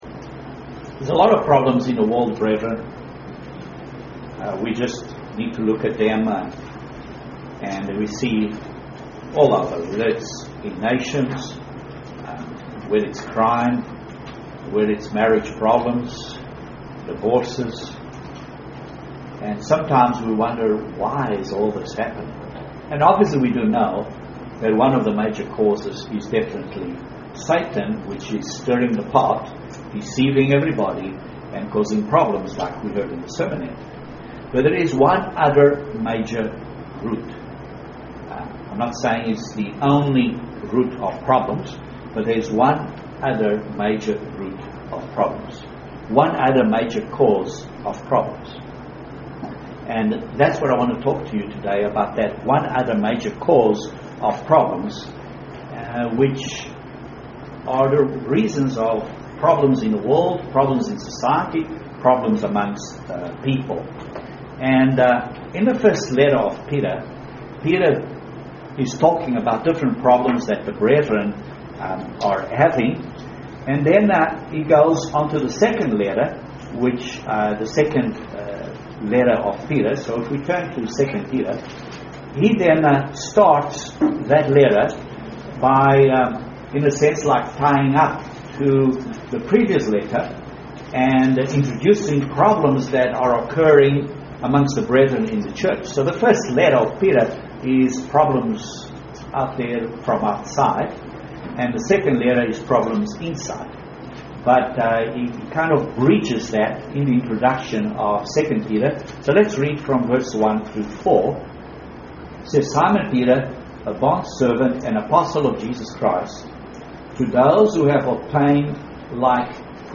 This sermon discusses one of the major sources of our problems